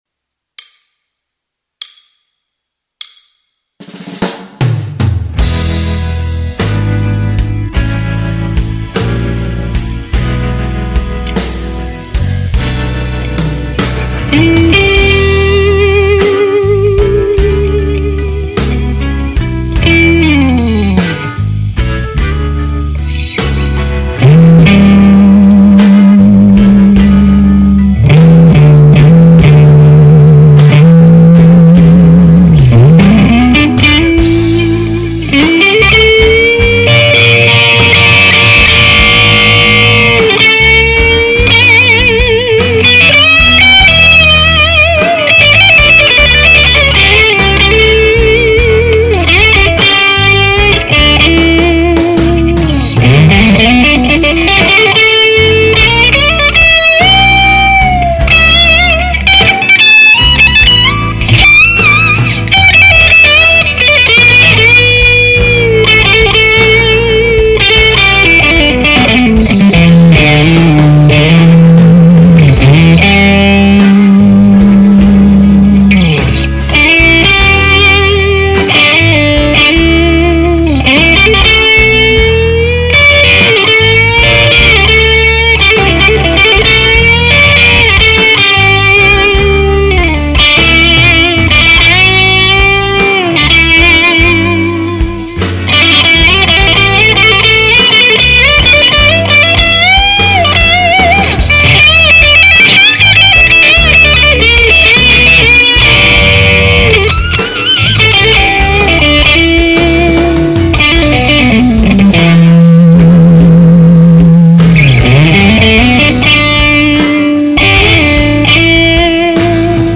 bluse jam ������������